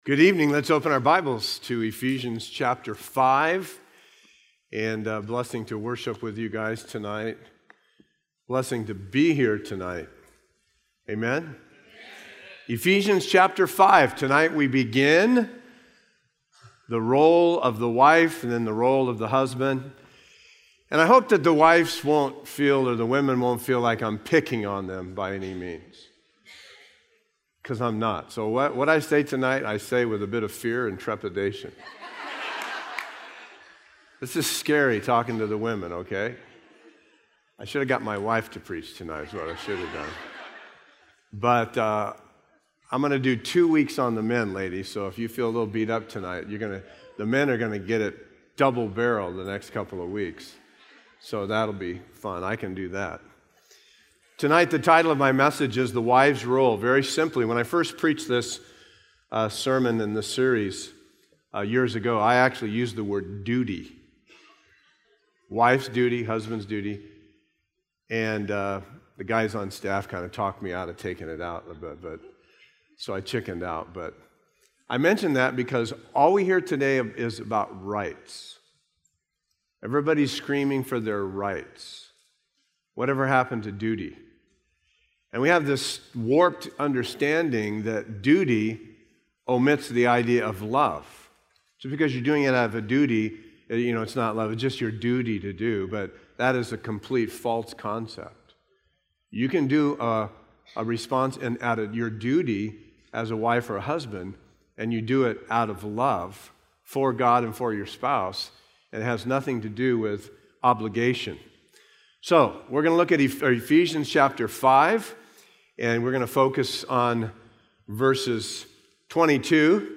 A verse-by-verse expository sermon through Ephesians 5:22-24